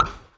Thumbpop.wav